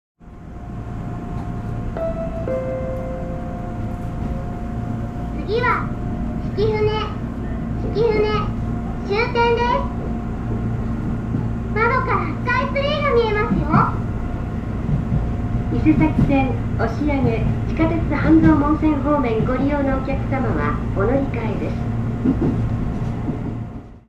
下町トレインでは、通常の放送の合間で「ソラカラちゃん」が案内をしてくれた。
車内放送例（曳舟ゆき小村井発車後）